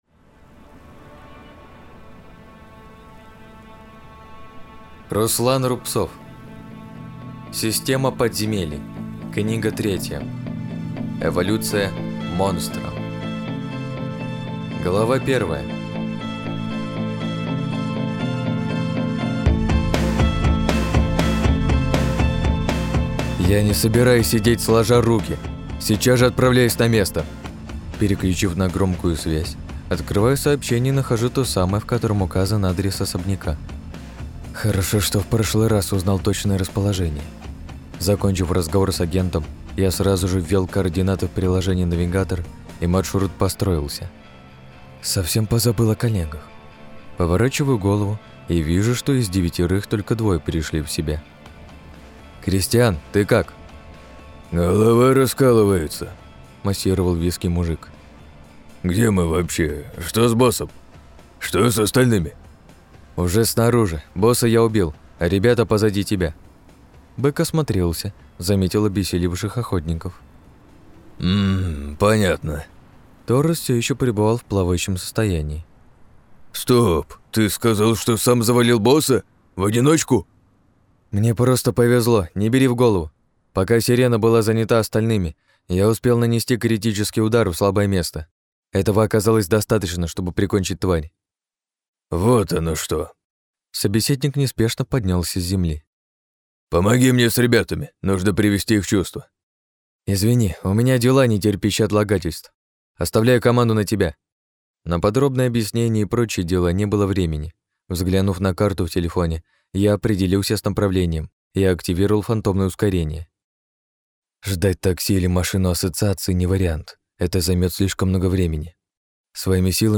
Аудиокнига - слушать онлайн